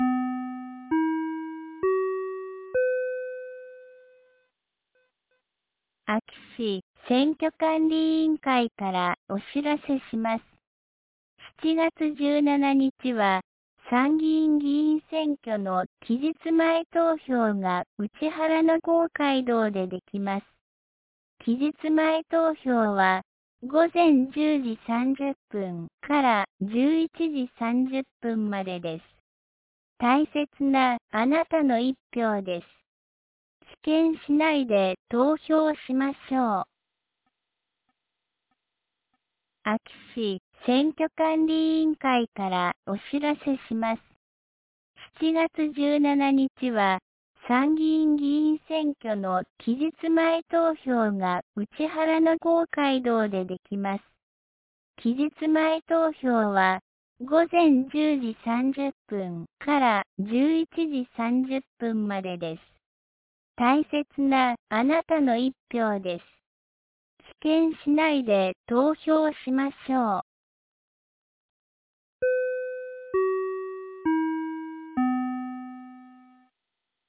2025年07月15日 09時01分に、安芸市より井ノ口へ放送がありました。